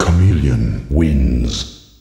The sound effect for Chameleon's probably not hard to find, either.
The same announcer is used for MK2 and the whole MK3 series, so it's probably the one from MKT.